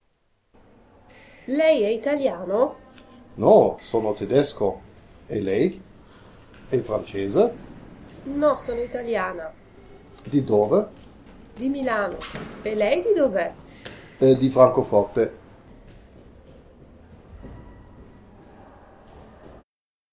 In jeder Übung sind Muttersprachler zu hören, die sich mit in einer Fremdsprache sprechenden Personen unterhalten.
1_-_sono_tedesco.mp3